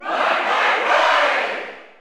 Category: Crowd cheers (SSBU) You cannot overwrite this file.
Roy_Koopa_Cheer_German_SSBU.ogg.mp3